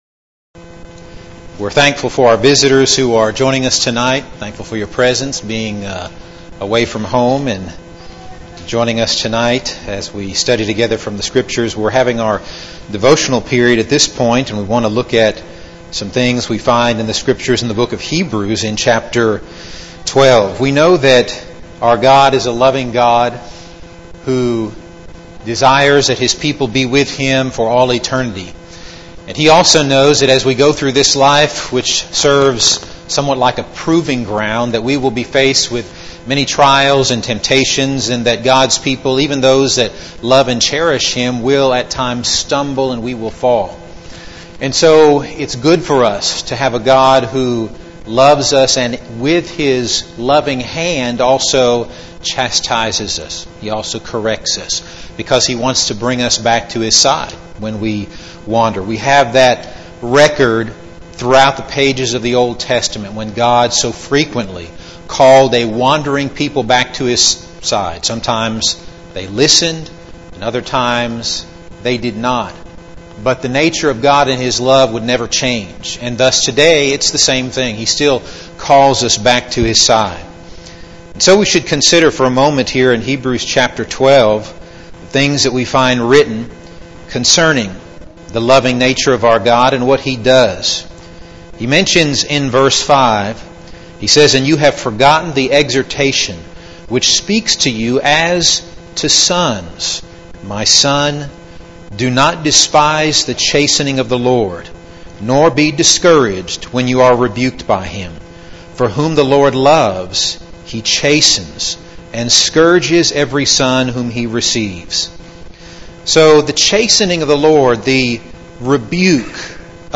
Midweek Bible Class « Youth Service